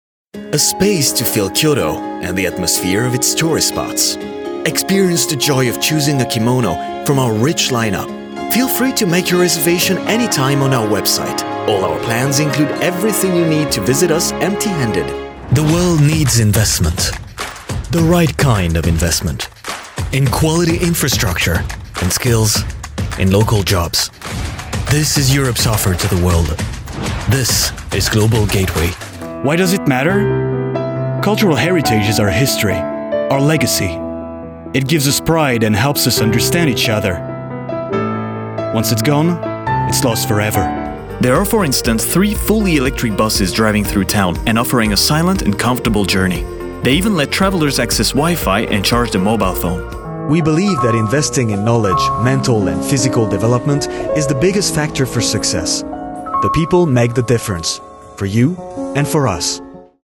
Engels (Internationaal)
Natuurlijk, Veelzijdig, Vertrouwd, Vriendelijk, Zakelijk
Corporate